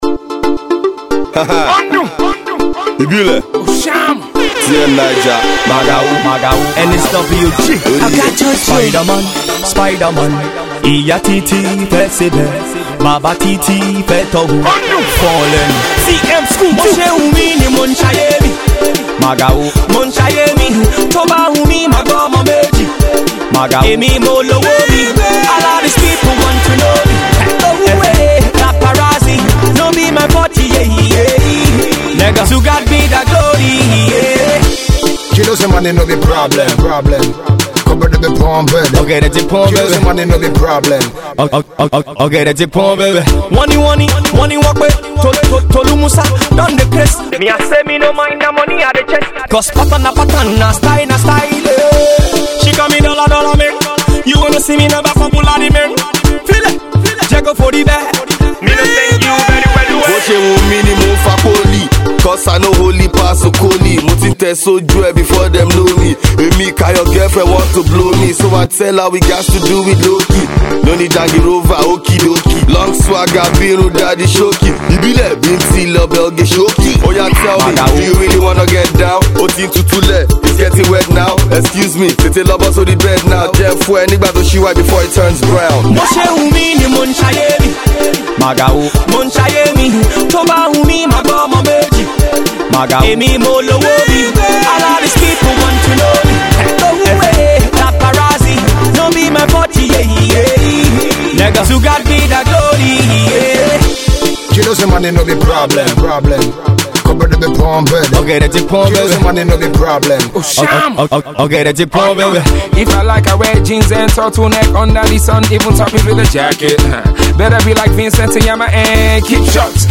Hip-HopStreet Pop
Street Pop